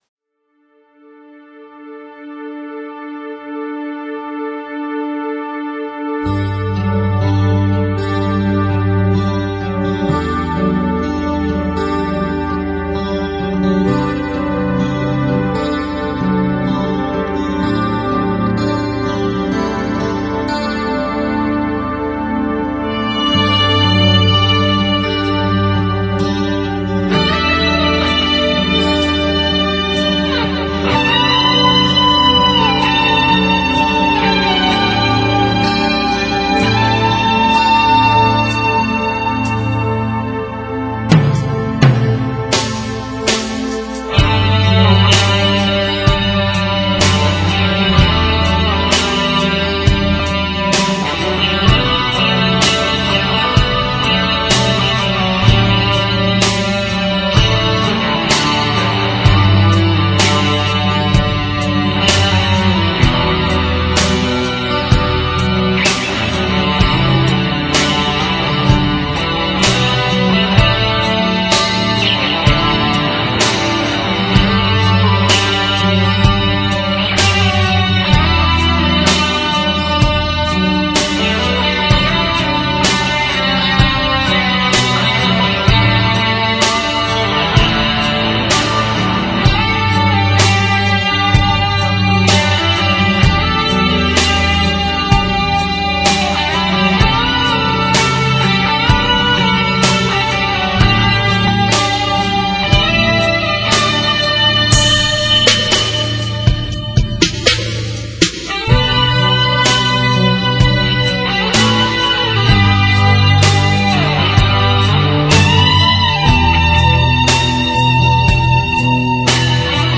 Así, he grabado dos canciones en las que la guitarra es el instrumento principal y la grabación se hizo en una computadora Pentium III a 800 mhz, con 256 MB en Ram y un Disco Duro de 20 GB.
Para la grabación ocupe una consola Yamaha de 16 canales, un micrófono Sure Beta 57, Ampli Roland Jazz Chorus 120, Guitarras Fender Telecaster e Ibanez JS 1000